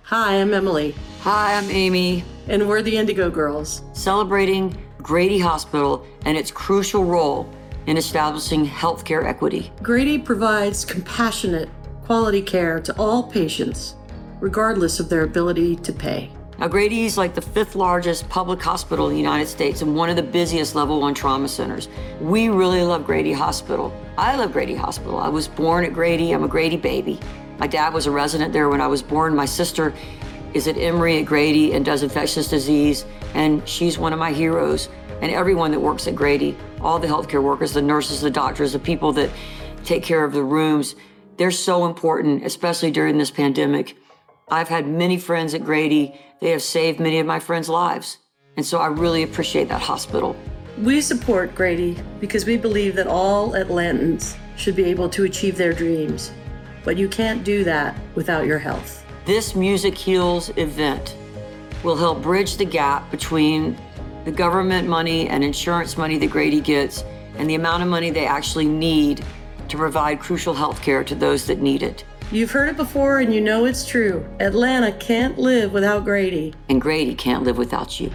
(captured from youtube)
02. talking with the crowd (indigo girls) (1:18)